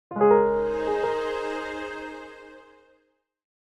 Download Free Success Sound Effects | Gfx Sounds
Mobile-game-piano-strings-victory-level-complete.mp3